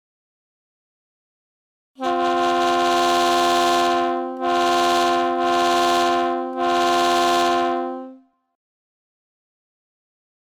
HD350B 2 No Super Apollo Air Horn